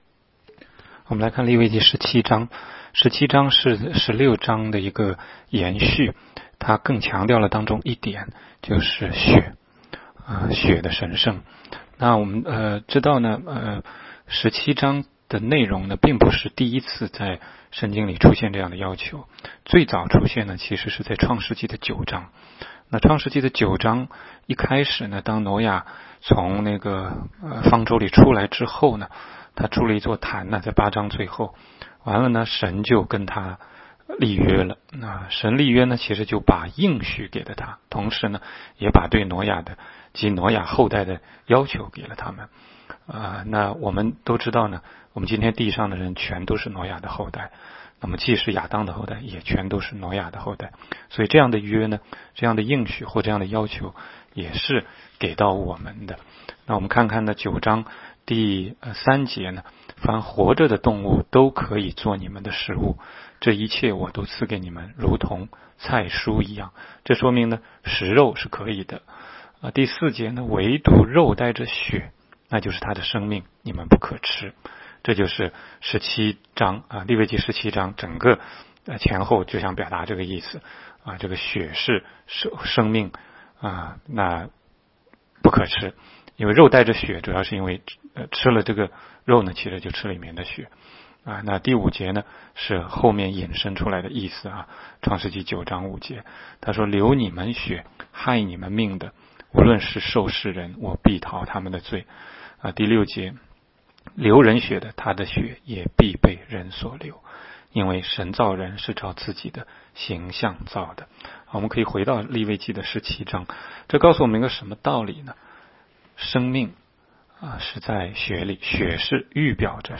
16街讲道录音 - 每日读经-《利未记》17章